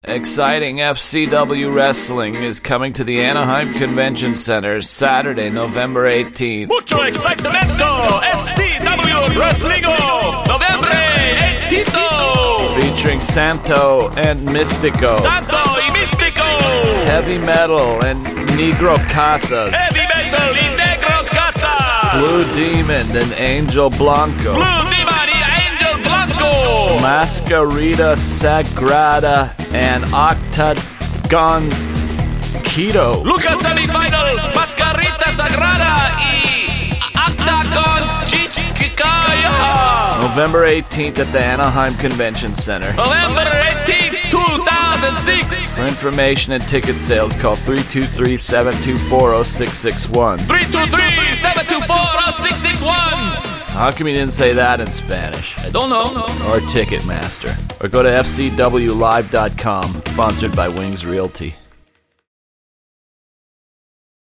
Commercials are on tracks 19 through 25 – some great stuff in both categories!